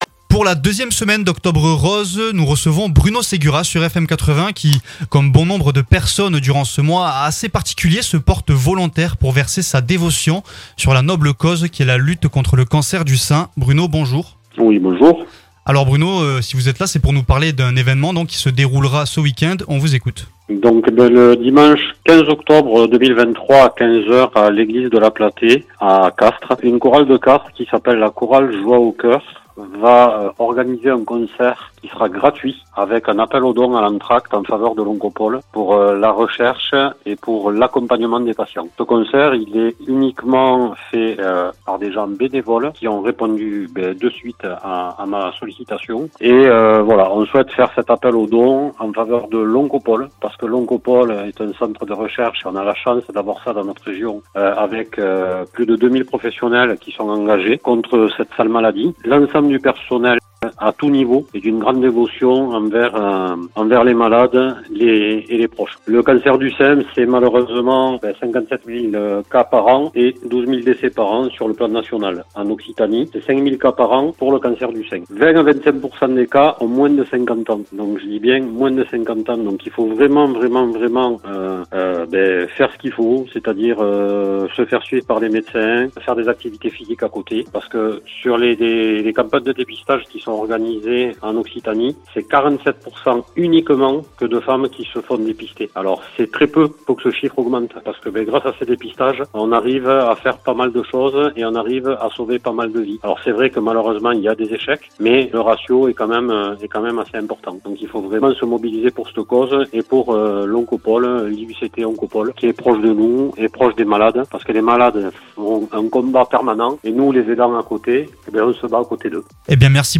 Concert chorale